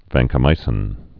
(văngkə-mīsĭn, vănkə-)